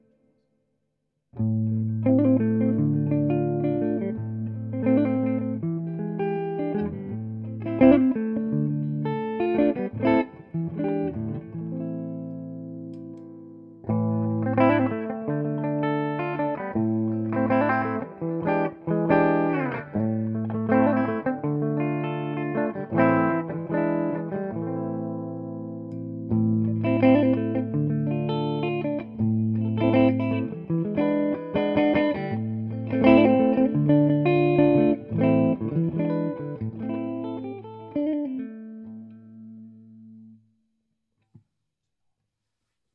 Le micro manche vous offrira des sonorités riches et enveloppées alors que la position chevalet se démarquera par sa brillance.
Son clair
gnbhumb_clair.mp3